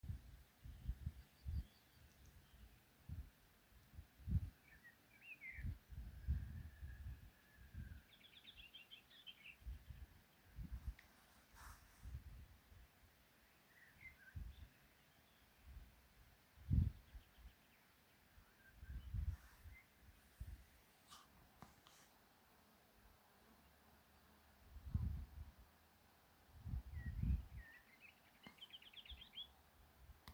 Booted Warbler, Iduna caligata
Administratīvā teritorijaLīvānu novads
StatusSinging male in breeding season